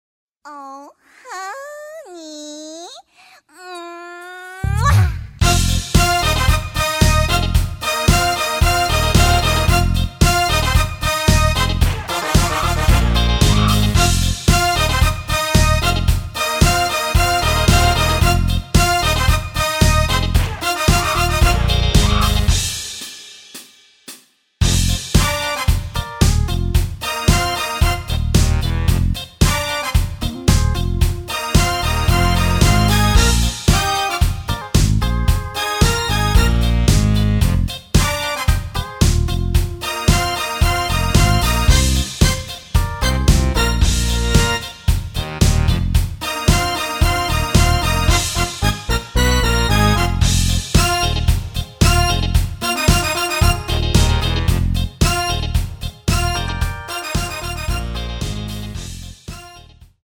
◈ 곡명 옆 (-1)은 반음 내림, (+1)은 반음 올림 입니다.